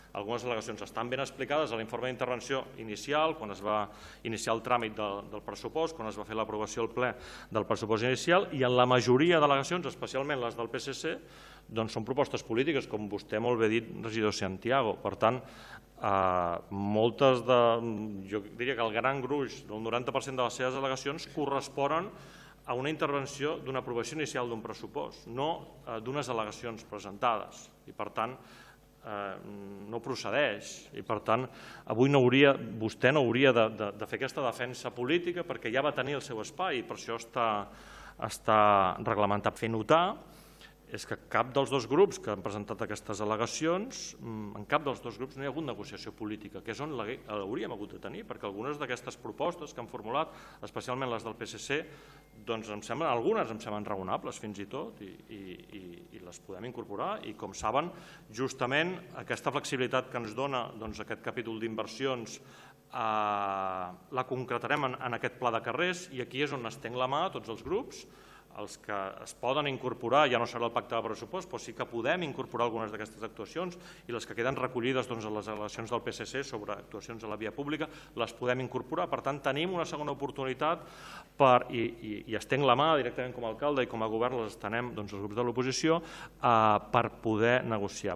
L’alcalde de Tiana, Isaac Salvatierra, ha respost al portaveu del PSC dient que la majoria de les al·legacions presentades eren propostes polítiques i que, tot i que algunes d’elles eren raonables, “aquesta negociació política s’hauria d’haver produït abans”: